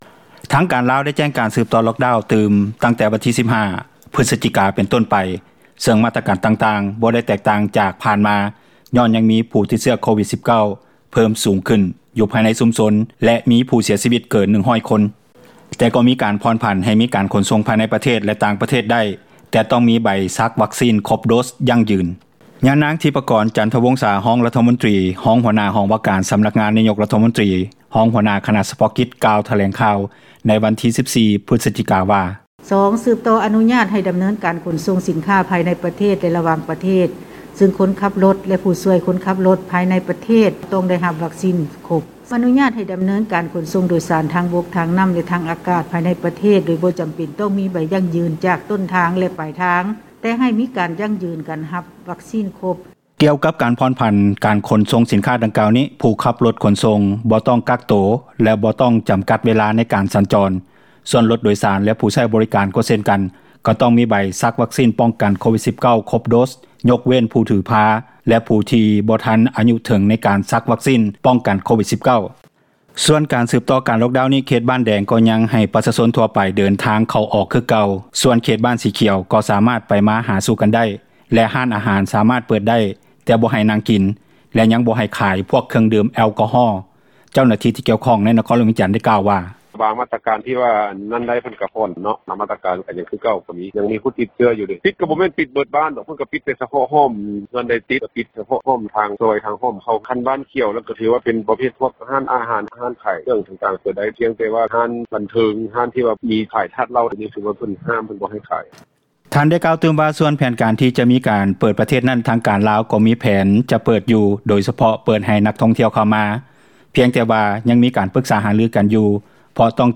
ຍານາງ ທິບພະກອນ ຈັນທະວົງສາ ຮອງຣັຖມົນຕຣີ ຮອງຫົວໜ້າຫ້ອງວ່າການ ສຳນັກງານນາຍົກຣັຖມົນຕຣີ ຮອງຫົວໜ້າຄນະສະເພາະກິຈ ກ່າວຖແລງຂ່າວໃນວັນທີ 14 ພຶສຈິກາວ່າ: